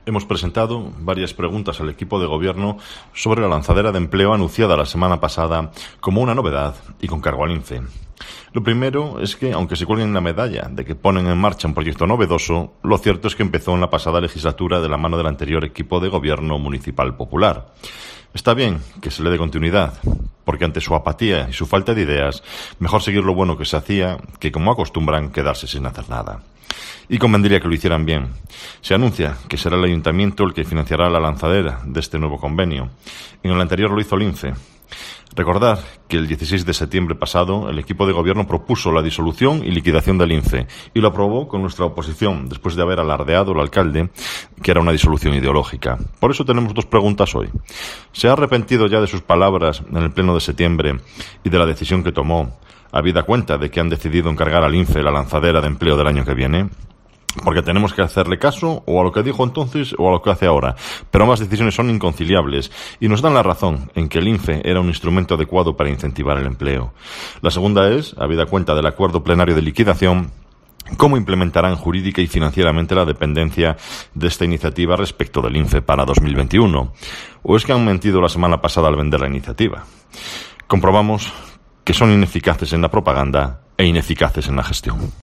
Escucha aquí al portavoz de los populares en la capital berciana, Marco Morala